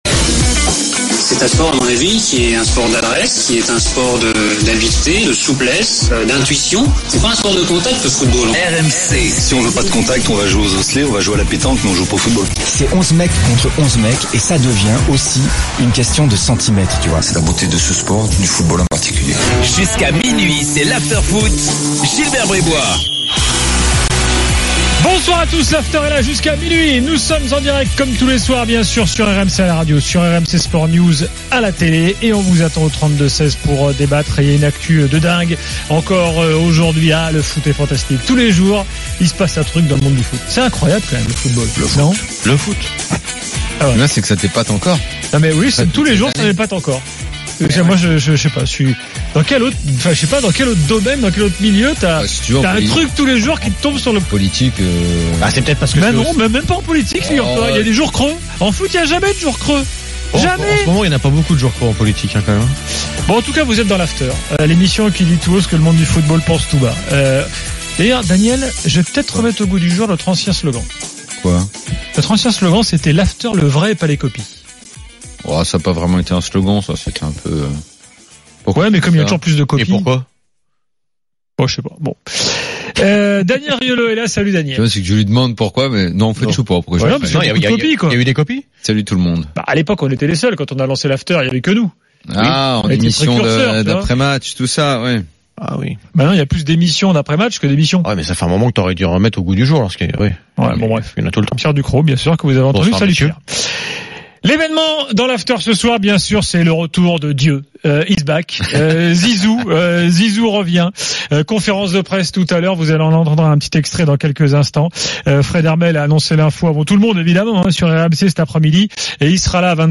Chaque jour, écoutez le Best-of de l'Afterfoot, sur RMC la radio du Sport.